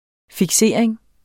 Udtale [ figˈseˀɐ̯eŋ ]